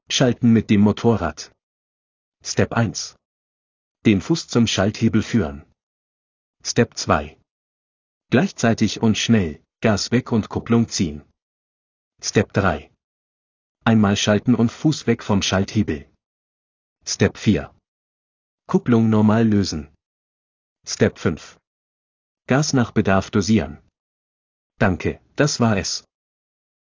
Bike-Schalten.m4a